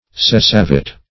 Cessavit \Ces*sa"vit\, n. [L., he has ceased.] [O. Eng. Law]